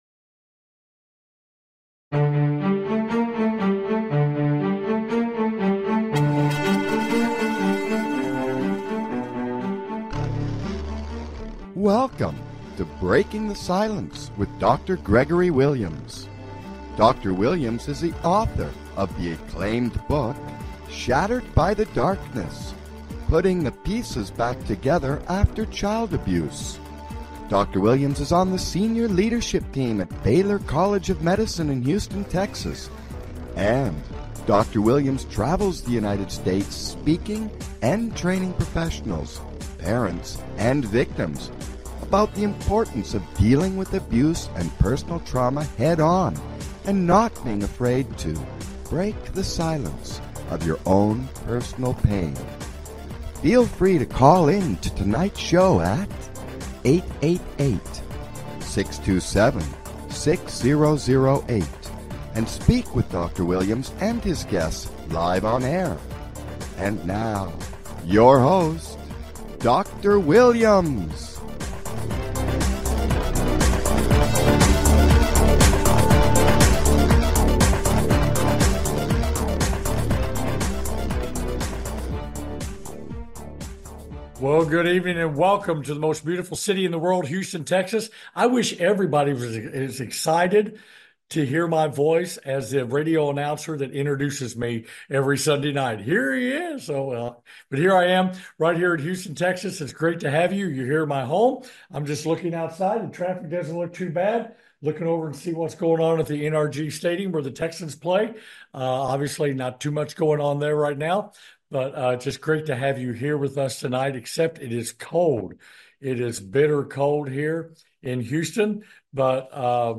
With Returning Special Guest, Dave Pelzer, Author of "A Child Called 'It'" for a continuation of his story
With Returning Special Guest, New York Time Best Selling Author; Dave Pelzer, Author of "A Child Called 'It'" for a continuation of his story.